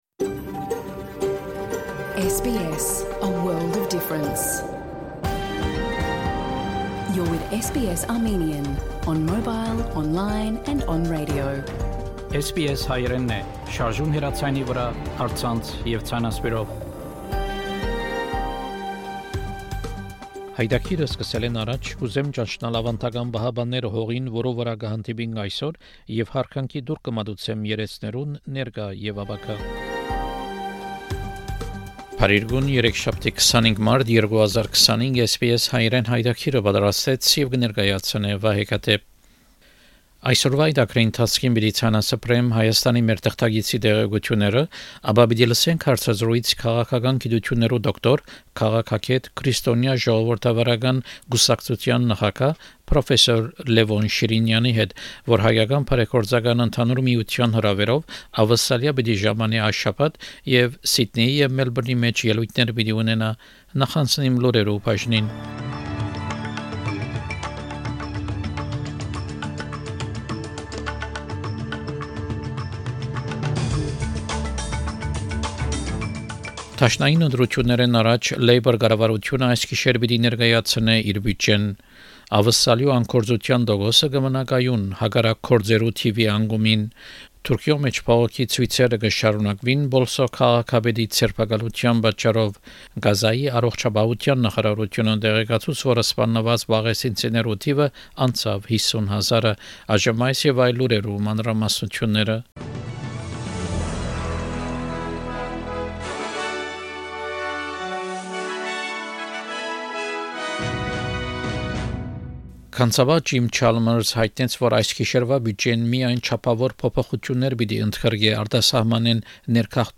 SBS Armenian news bulletin from 25 March 2025 program.